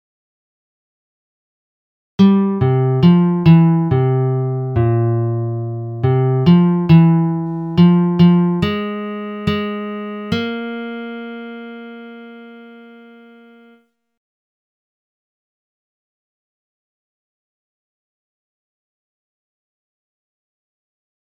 Key written in: C Major
Type: Other male
Each recording below is single part only.